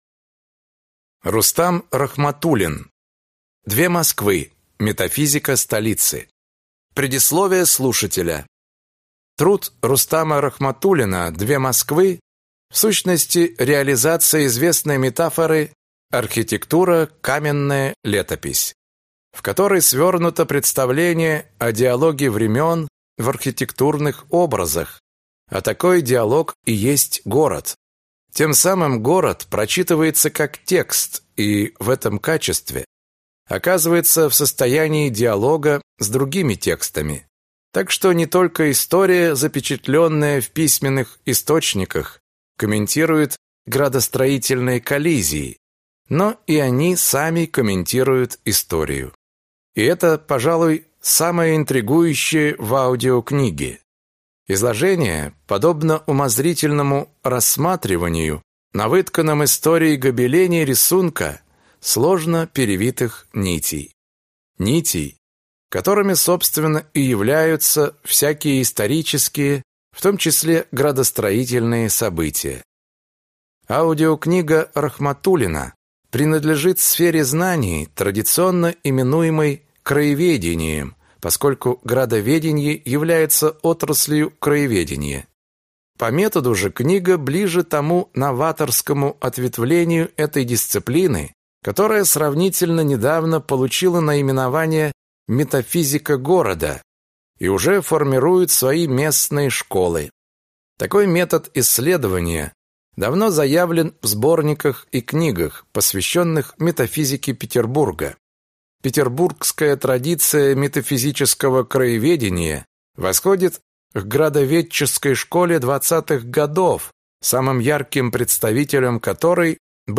Аудиокнига Две Москвы: Метафизика столицы | Библиотека аудиокниг